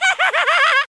Everquest Skeleton Efeito Sonoro: Soundboard Botão